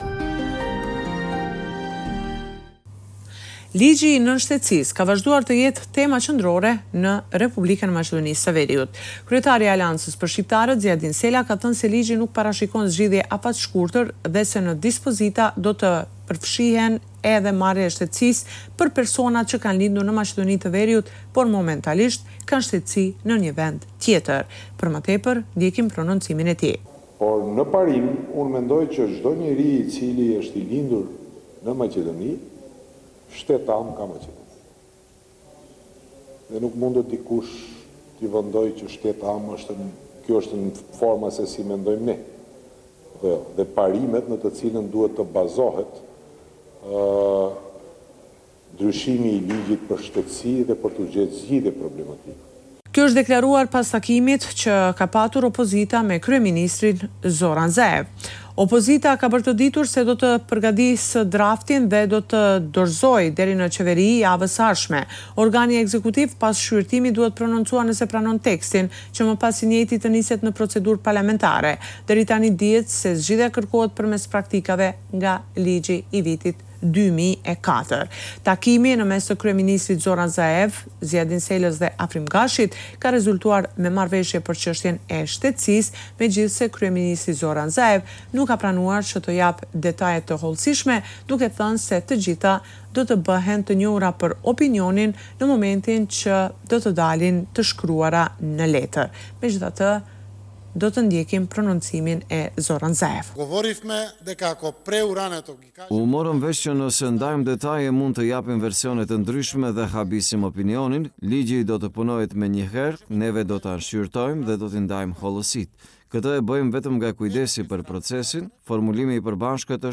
Raporti me te rejat me te fundit nga Maqedonia e Veriut.